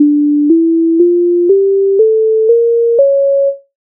Minor harmonic mode
MIDI файл завантажено в тональності d-moll
Standartni_poslidovnosti_d_moll_harmonic_mode.mp3